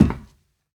StepMetal7.ogg